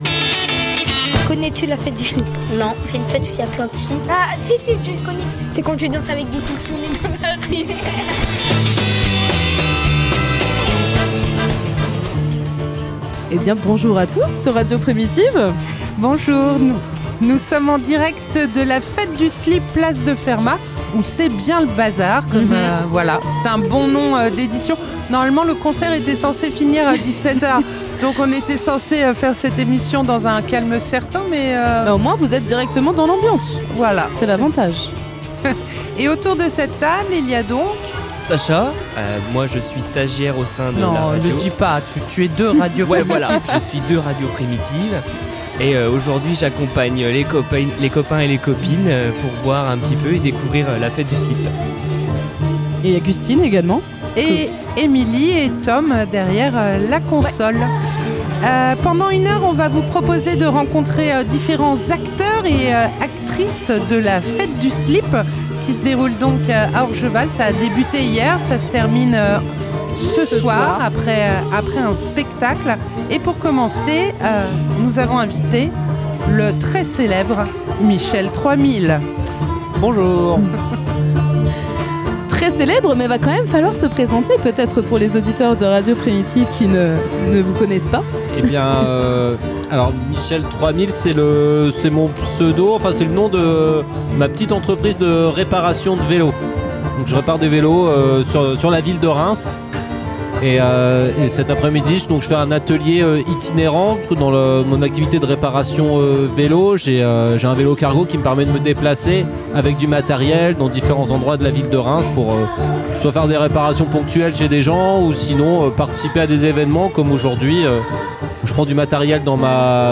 Le samedi 4 avril, Radio Primitive avait installé son studio mobile, place de Fermat, afin de participer à la fête du Slip organisée par l'association TRAC !